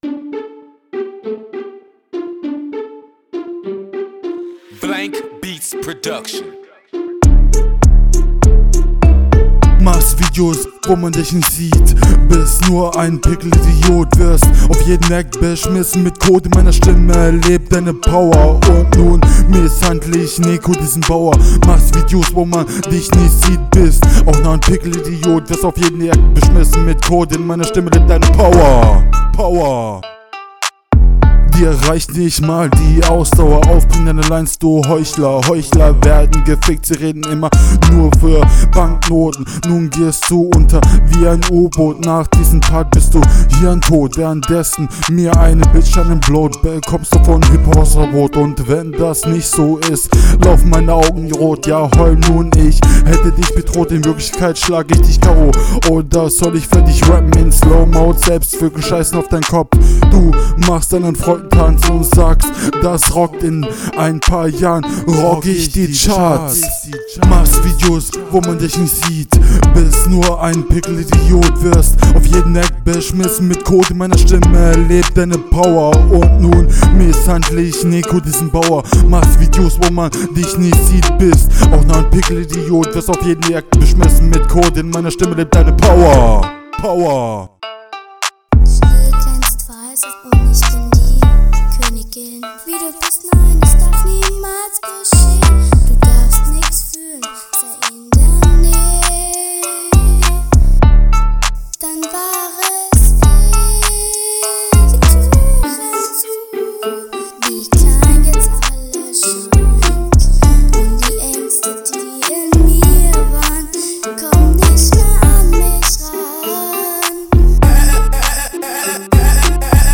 ich finde die stimme ist gut laut nicht zu laut kommst gut auf den Beat …
Der Gesang am Ende bitte nicht nochmal.
Versuch etwas mehr auf deine Betonung zu achten und allgemein deinen Stimmeinsatz.